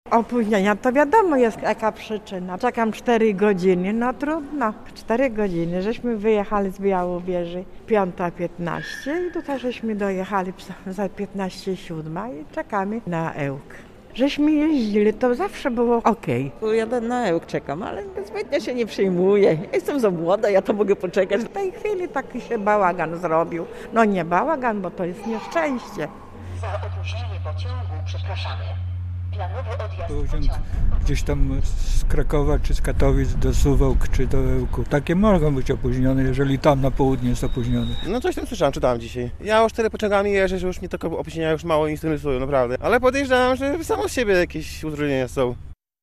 Z pasażerami na dworcu w Białymstoku rozmawiał